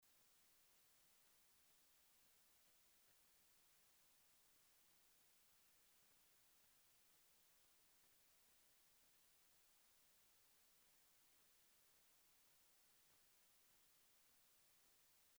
I hear a little hiss!
View attachment noMic.mp3
The tiny blip at 60Hz shows mains pickup from the room, not internal supply hum since that would be at 120Hz.
Yes, this was an office with multiple computers. No sound proofing in sight.